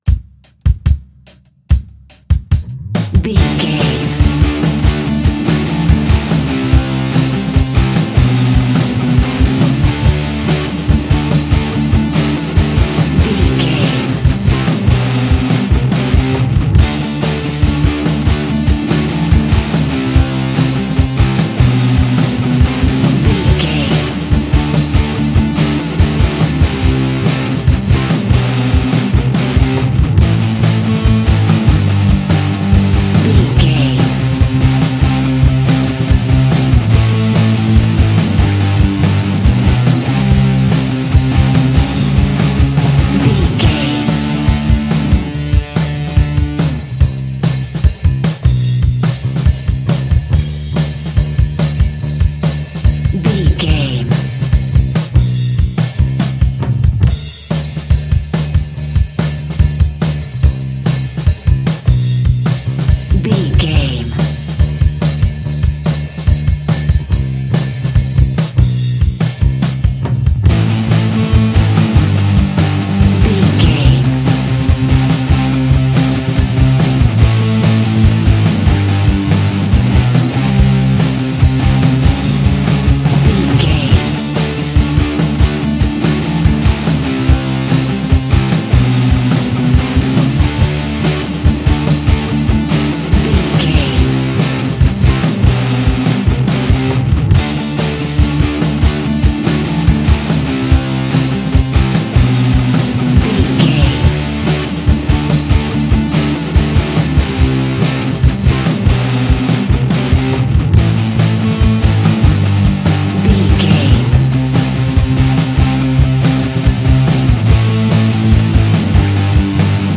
Aeolian/Minor
groovy
powerful
electric guitar
bass guitar
drums
organ